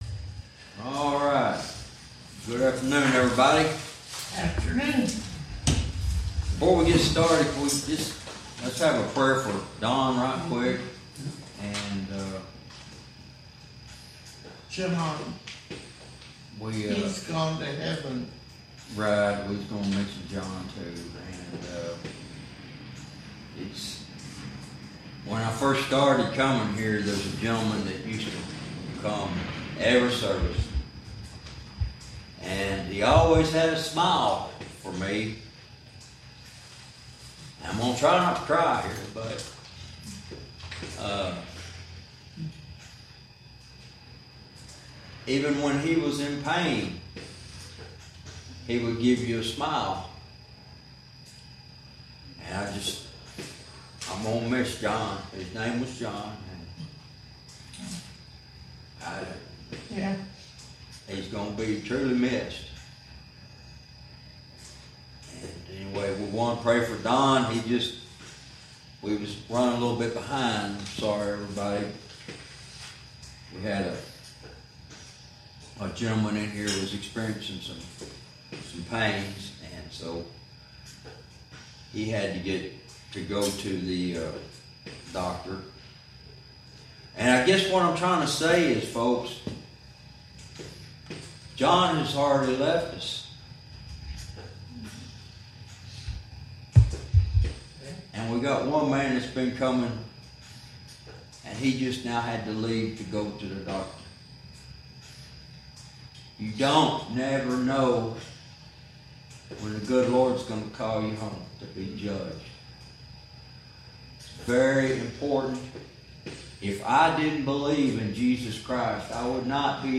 Verse by verse teaching - Jude Lesson 61 Verse 14